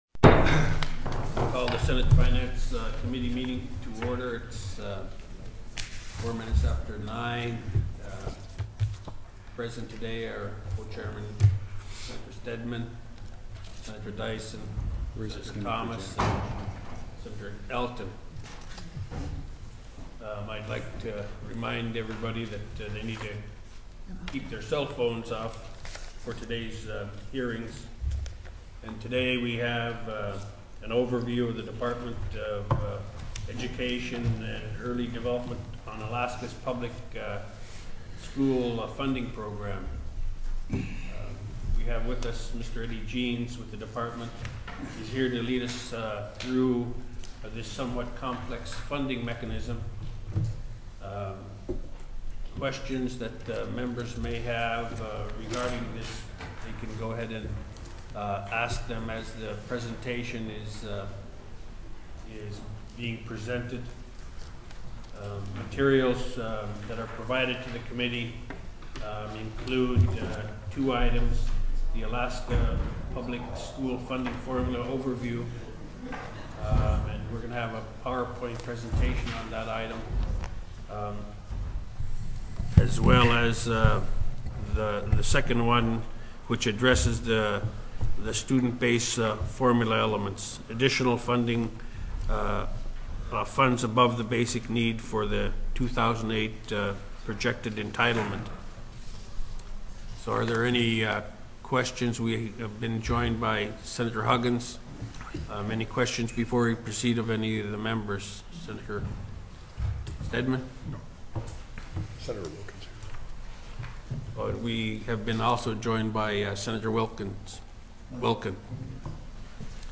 SUMMARY INFORMATION Presentation by the Department of Education and Early Development. Public School Funding Formula.